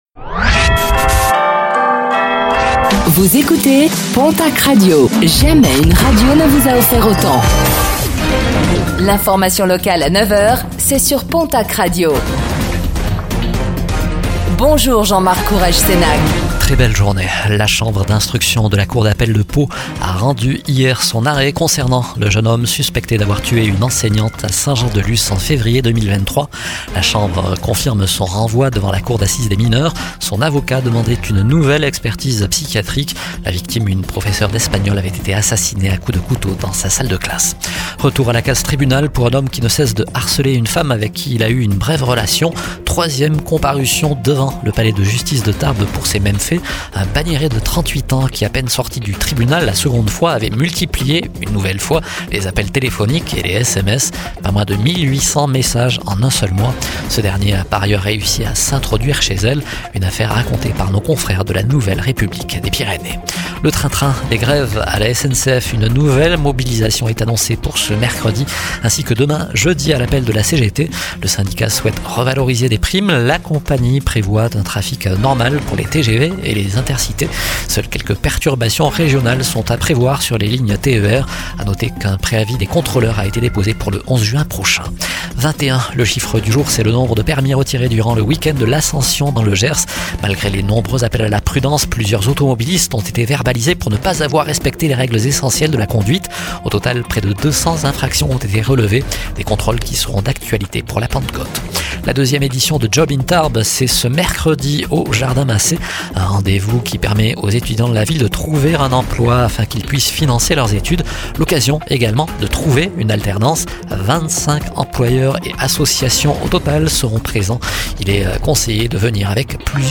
Infos | Mercredi 04 juin 2025